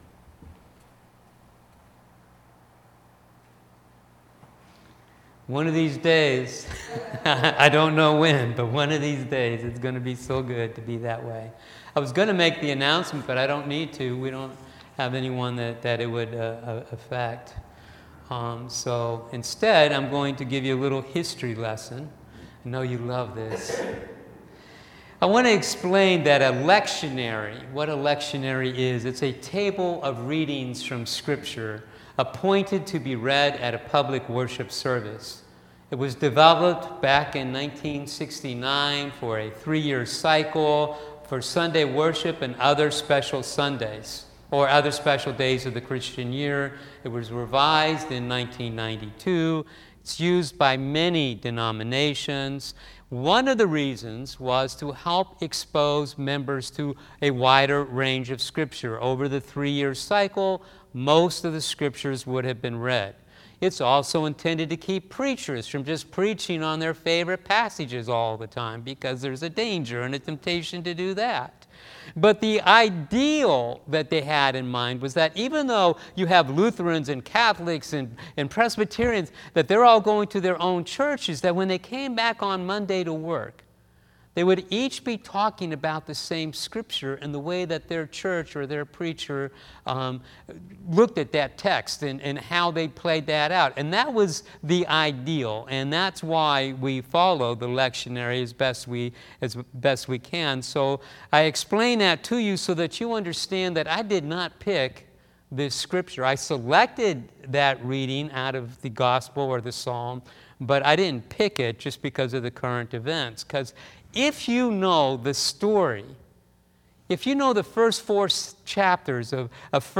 The sermon today was just so right on for our time.